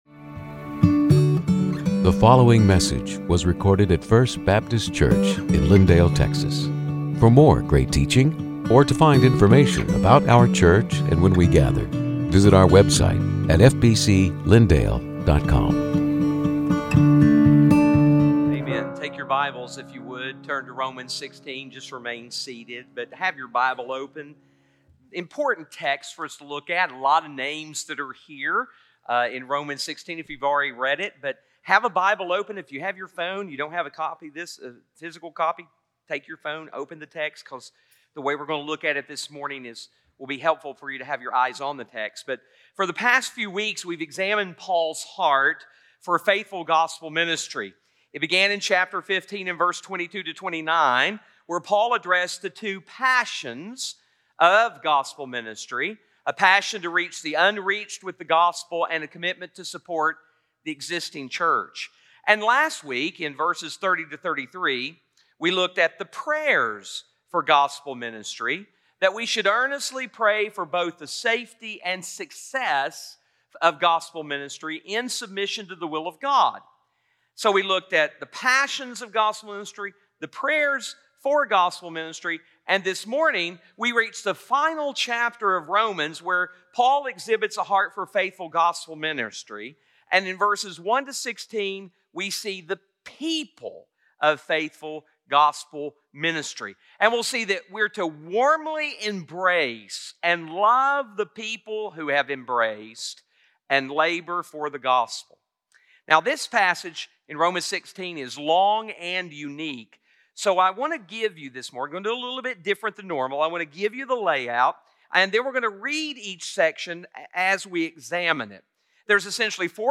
Sermons › Romans 16:1-16